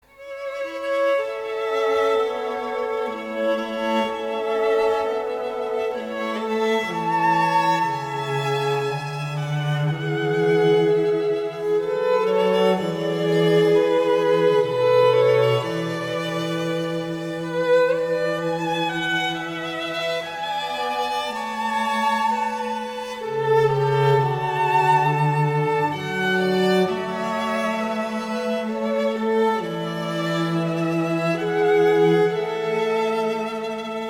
Kategorien: Klassische